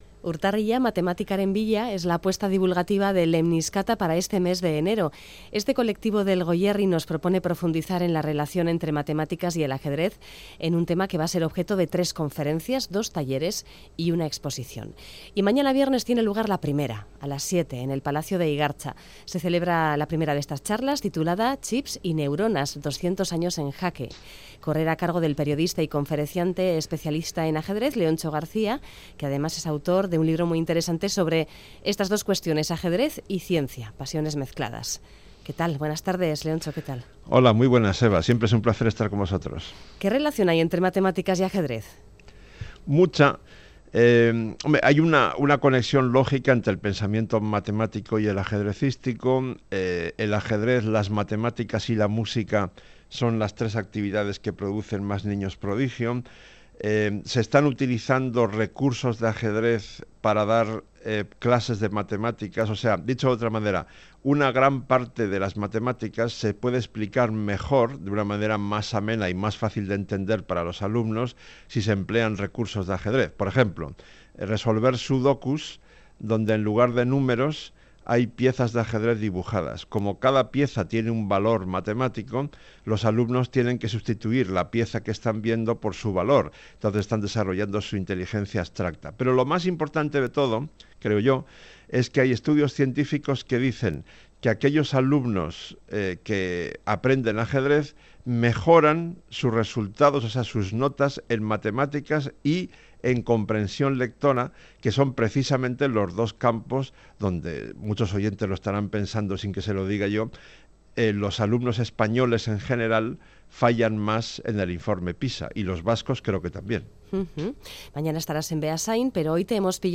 El periodista especializado en ajedrez Leontxo García explica la relación que existe entre el ajedrez y las matemáticas y su uso como herramienta de educación